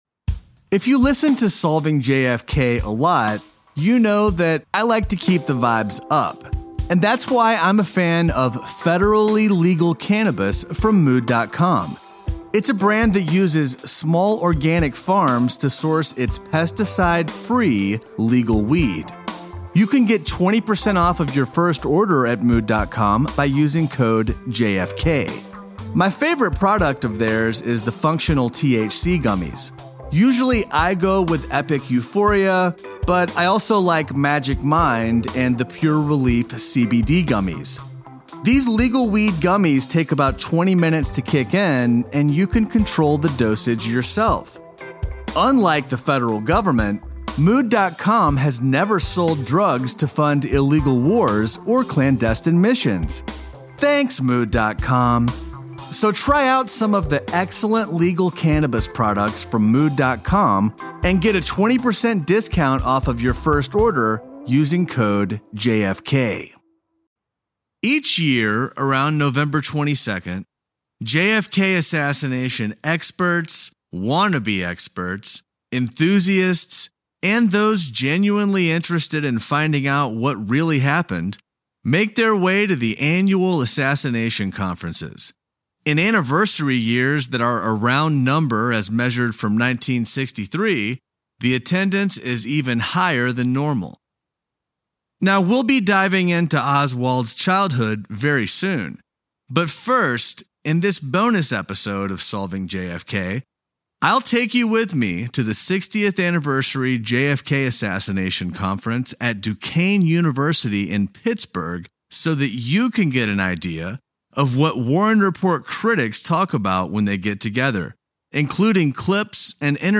In this episode, we take a field trip to Pittsburgh for the 60th Anniversary JFK Assassination Conference at the Cyril Wecht Institute at Duquesne University.